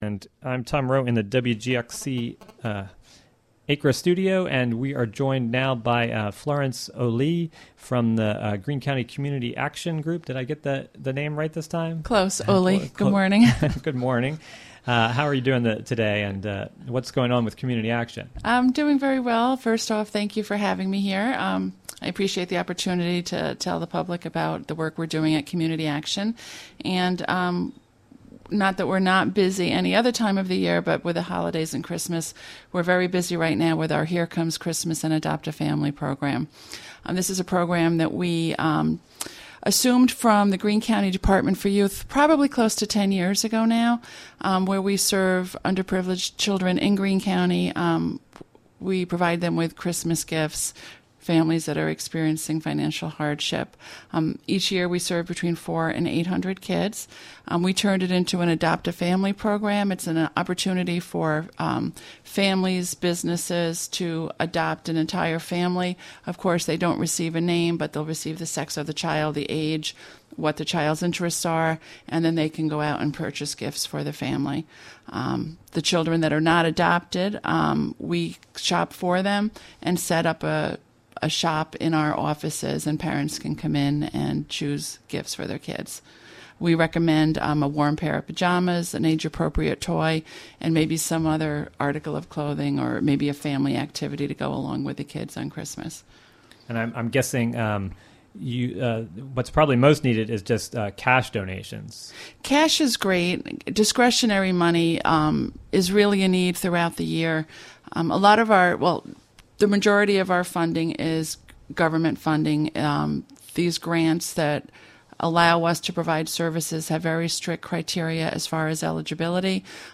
Local interviews, local news, regional events, mus...